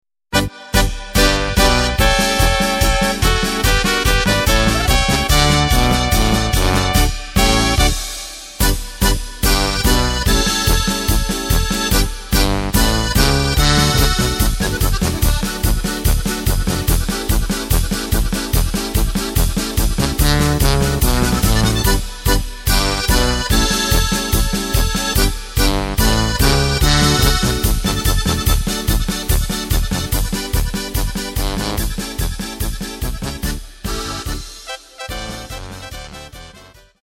Takt: 2/4 Tempo: 145.00 Tonart: D
Polka im Oberkrainer Stil!